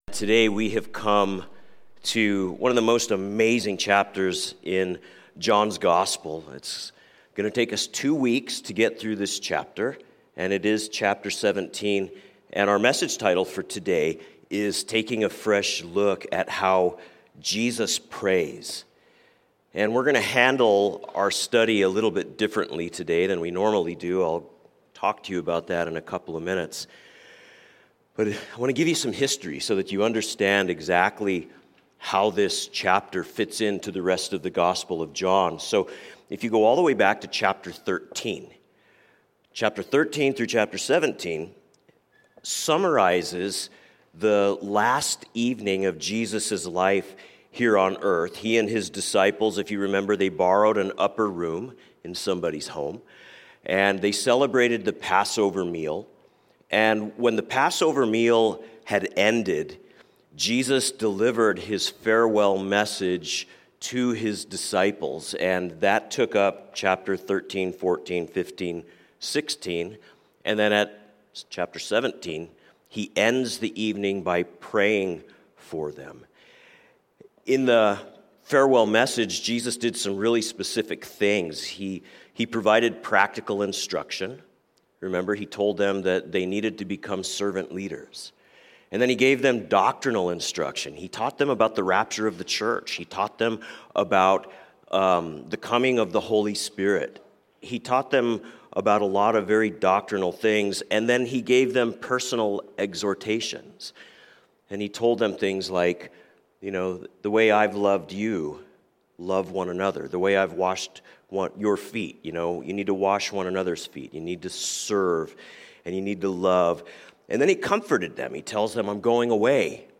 A message from the series "Sunday Morning."